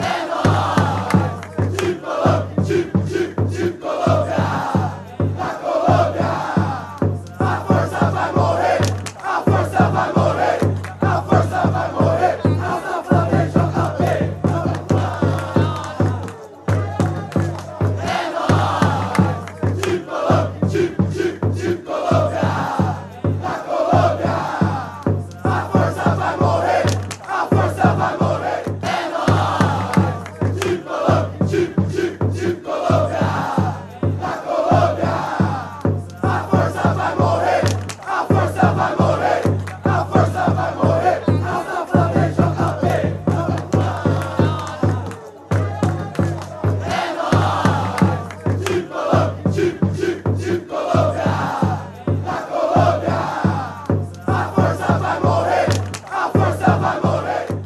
soccer chant